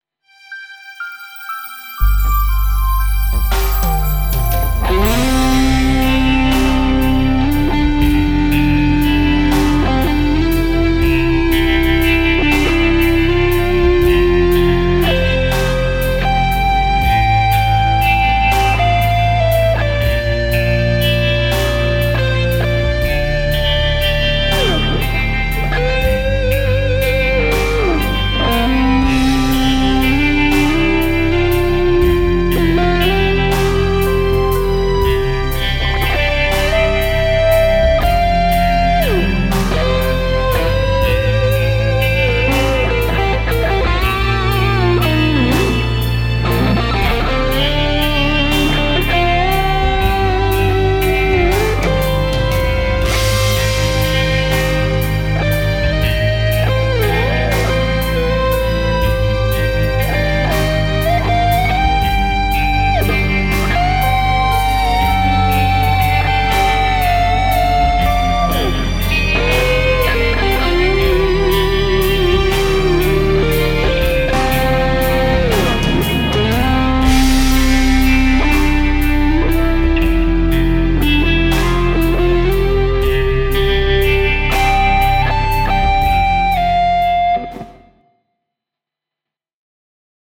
Haastavia osuuksia joitakin mutta pääosin selkeää harmoniaa, hidas tempo tuo myös omat haasteensa.
- Laadi annetun taustan päälle solistinen osuus valitsemallasi instrumentilla